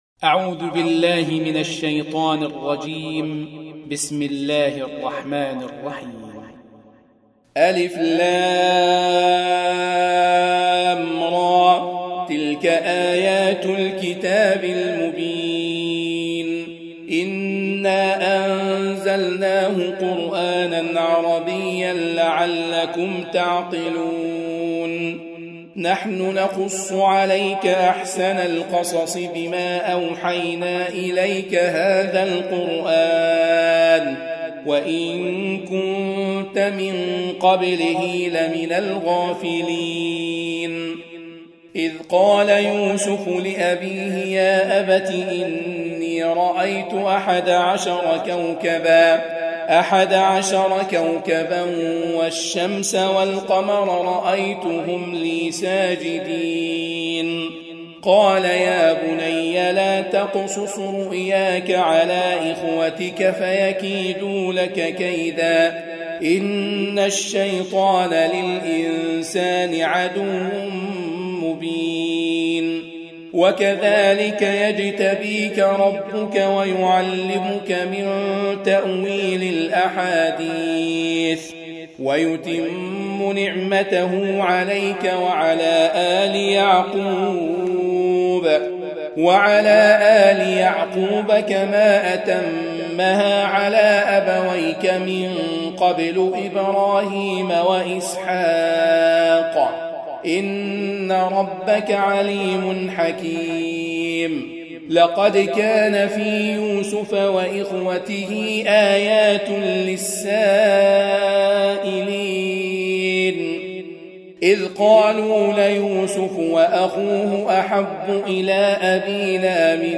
12. Surah Y�suf سورة يوسف Audio Quran Tarteel Recitation
Surah Sequence تتابع السورة Download Surah حمّل السورة Reciting Murattalah Audio for 12.